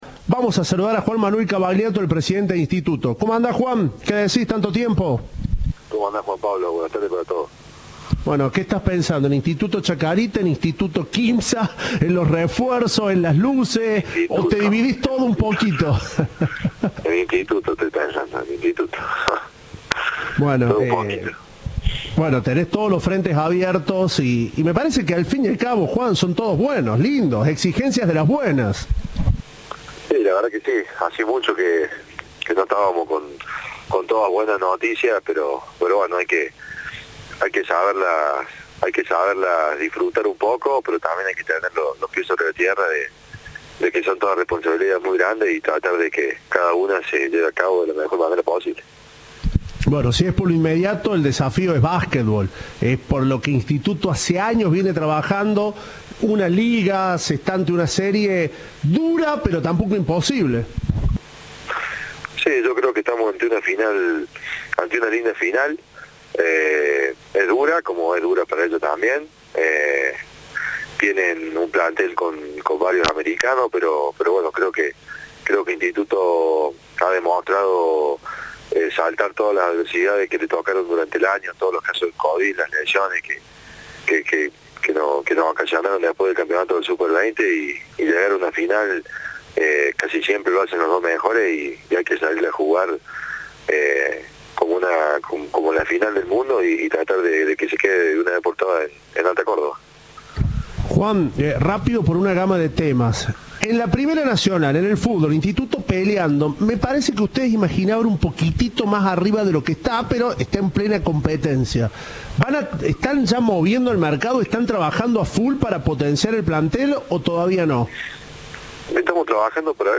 Entrevista de Tiempo de Juego.